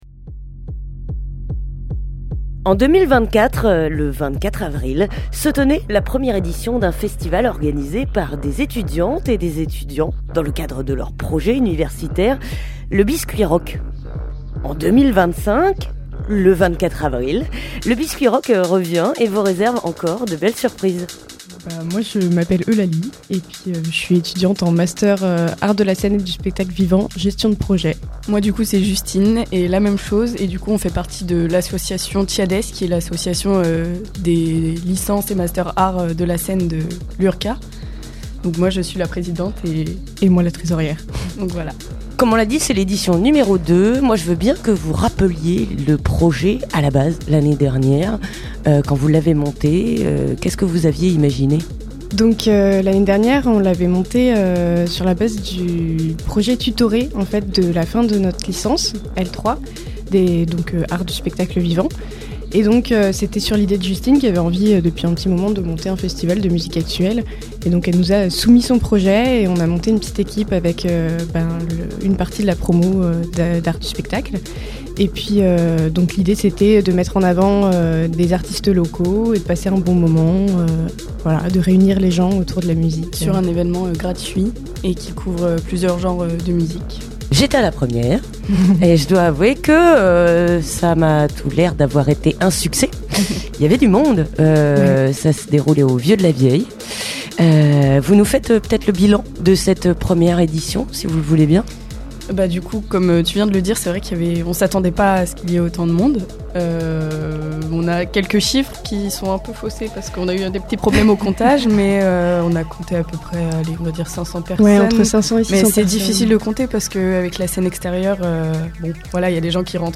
Interview des organisatrices (12:55)